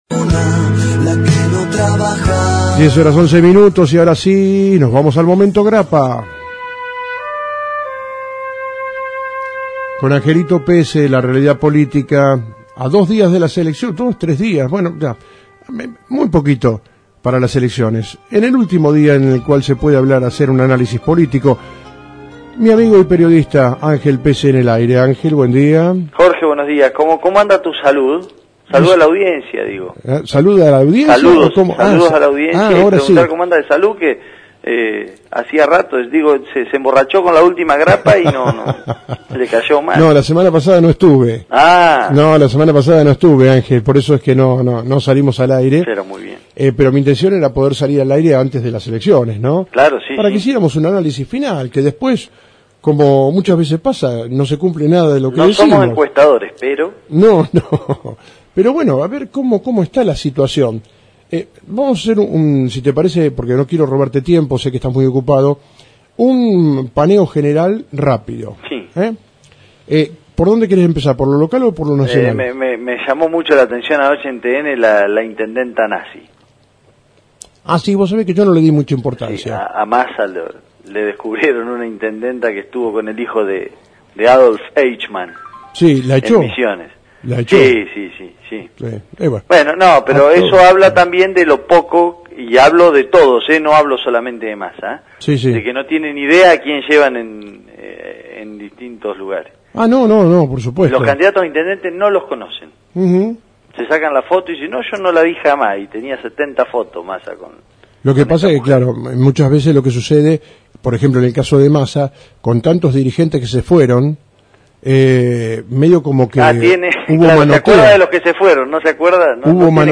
Análisis Político - Momento Grapa como Titularon los Oyentes :: Radio Federal Bolívar